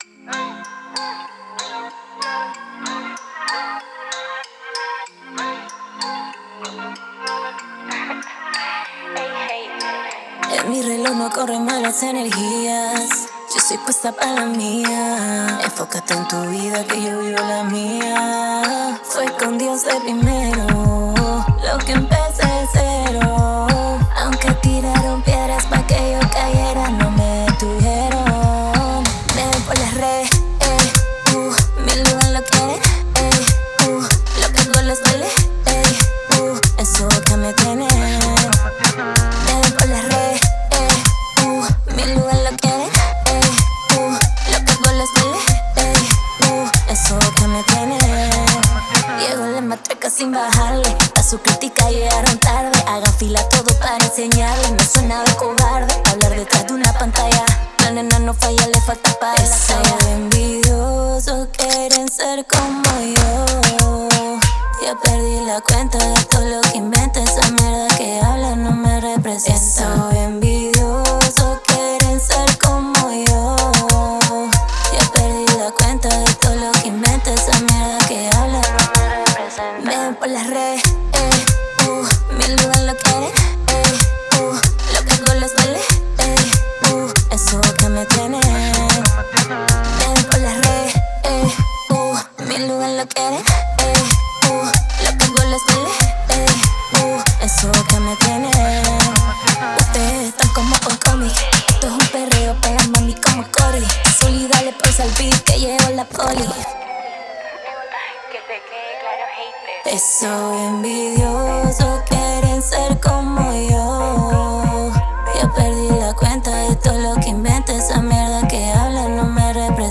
canción con un estilo muy urbano.
es un Reggaeton nativo